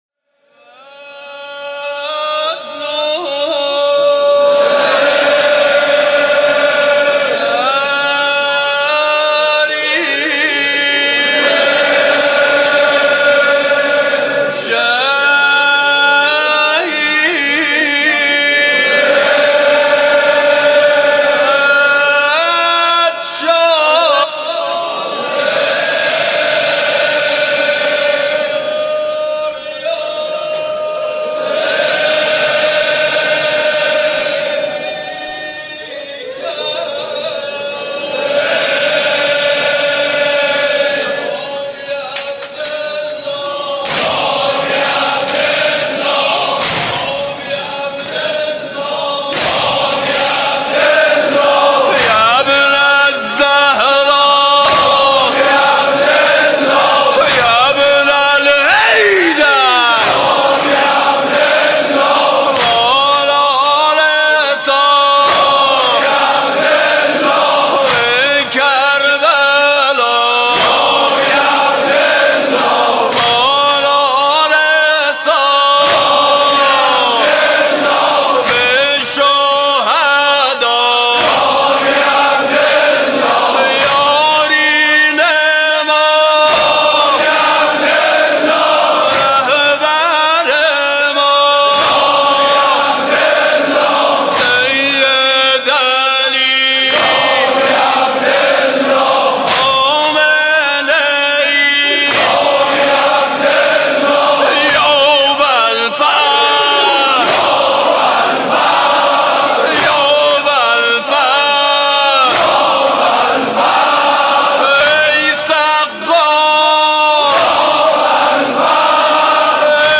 «محرم 1396» (شب اول) نوحه خوانی: مظلوم حسین